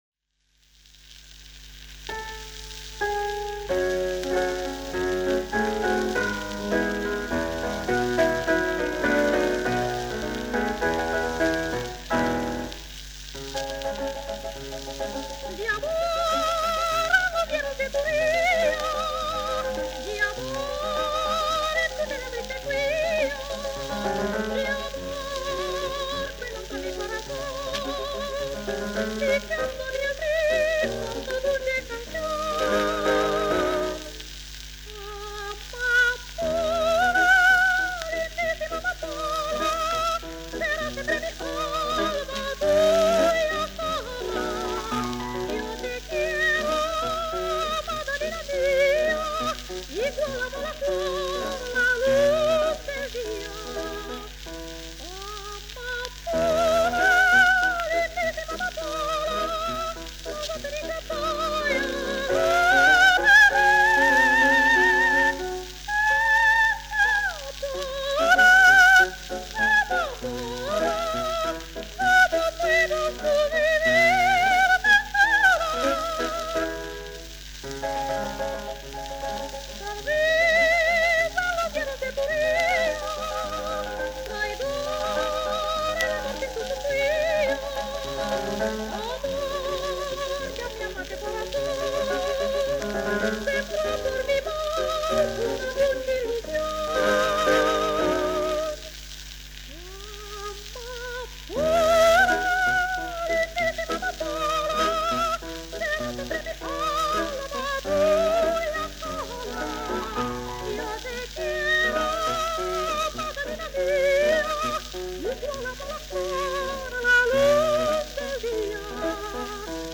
soprano l-coloratura